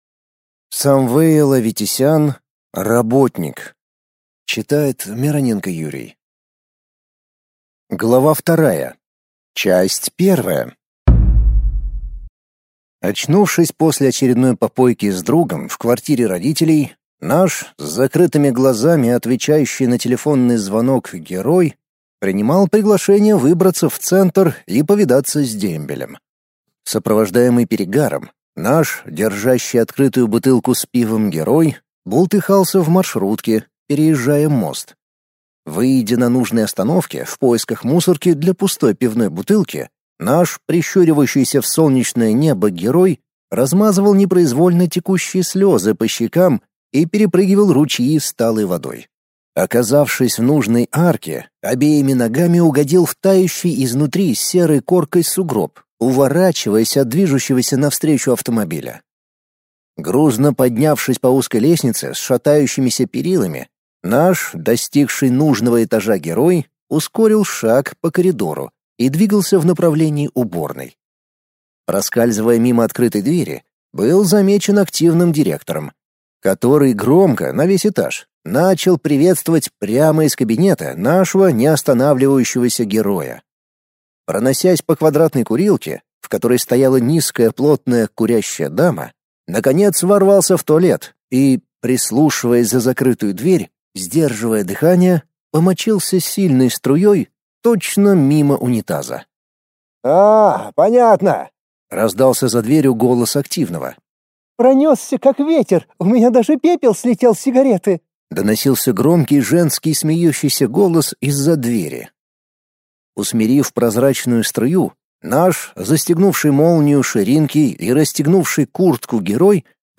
Аудиокнига Работник 2 | Библиотека аудиокниг
Читает аудиокнигу